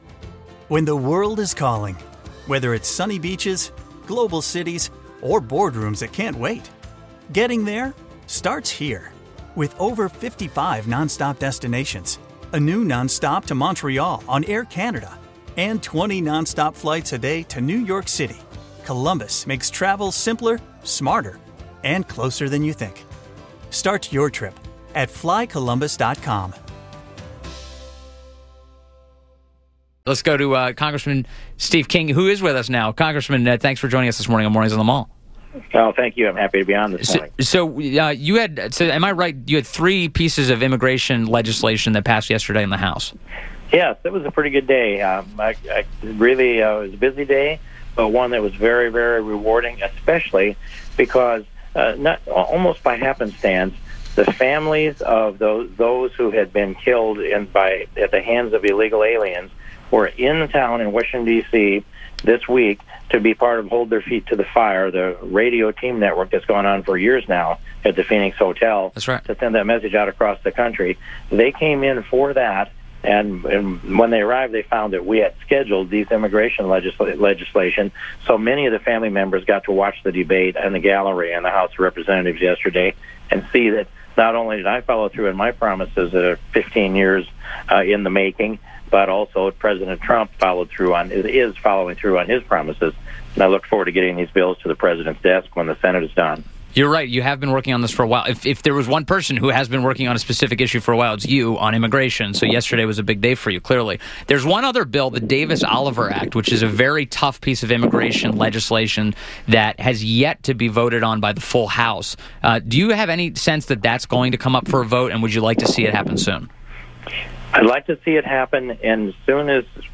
WMAL Interview - Congressman Steve King 06.30.17